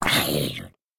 Sound / Minecraft / mob / zombie / hurt2.ogg
should be correct audio levels.